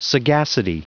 Prononciation du mot sagacity en anglais (fichier audio)
Prononciation du mot : sagacity